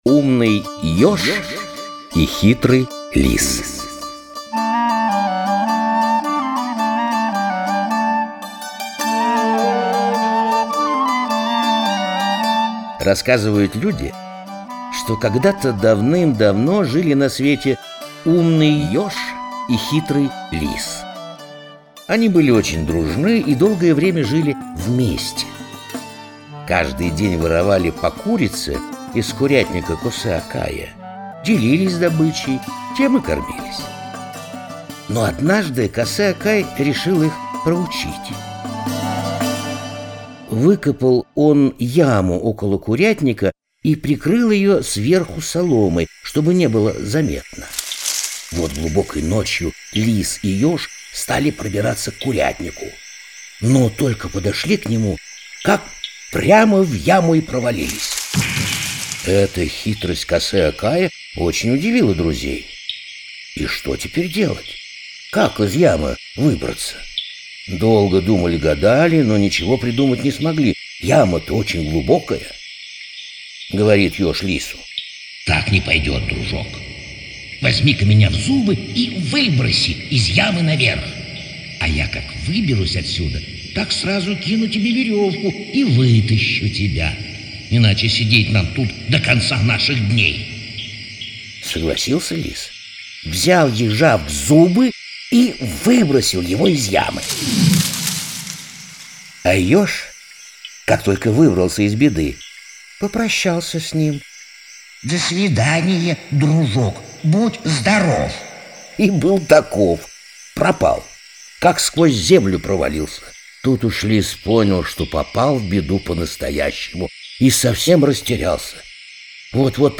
Умный ёж и хитрый лис - крымскотатарская аудиосказка